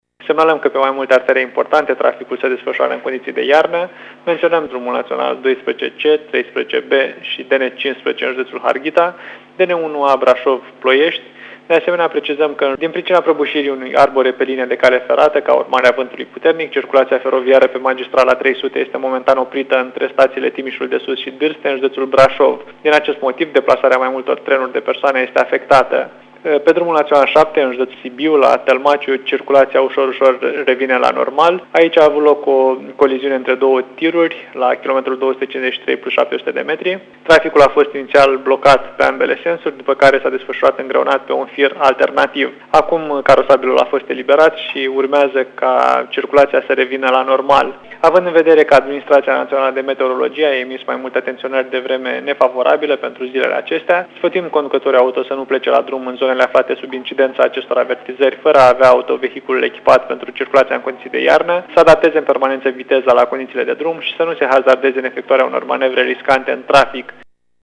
Raport Trafic